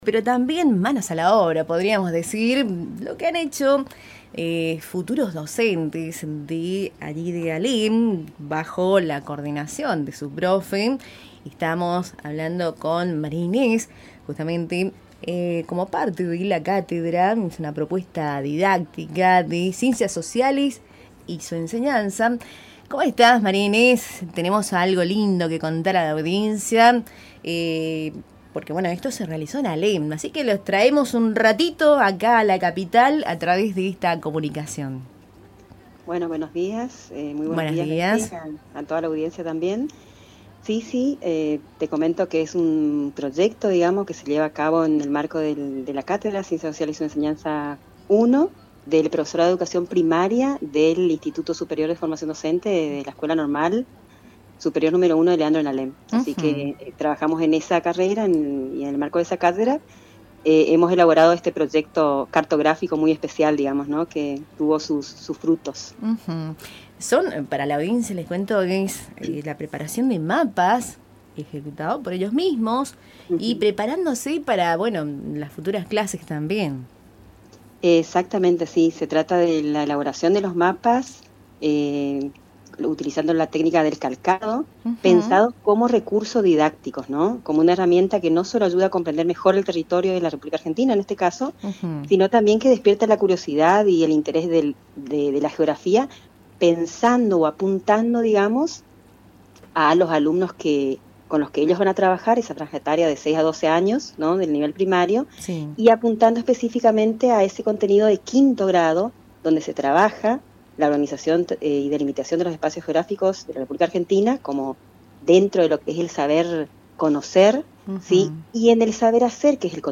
Entrevista realizada en Radio Tupambaé